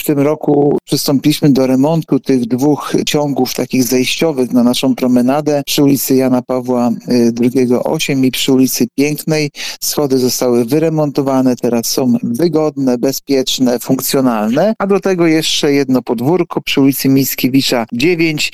powiedział Artur Urbański, zastępca prezydenta Ełku.